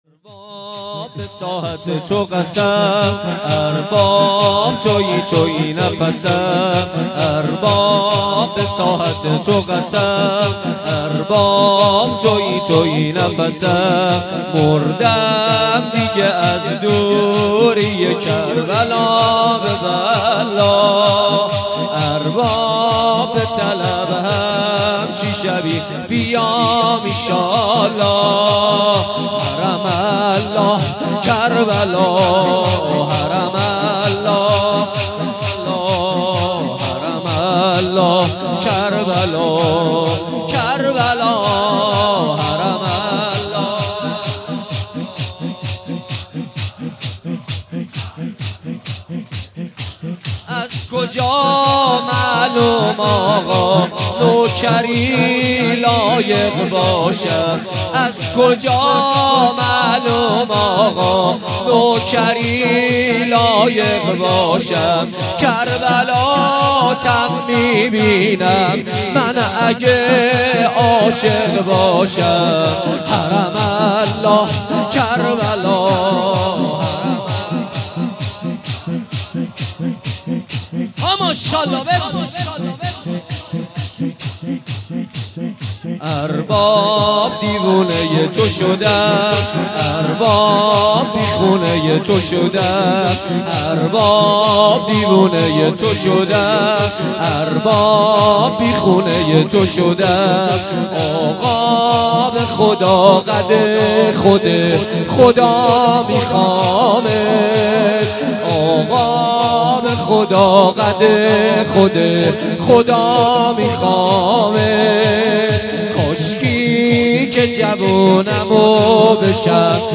با مداحی کربلایی محمد حسین پویانفر برگزار گردید.
شب بیست و هفتم صفر ریحانه النبی(ص) با مداحی کربلایی محمد حسین پویانفر برگزار گردید. کد خبر : ۴۳۹۲۵ عقیق:صوت این مراسم را بشنوید.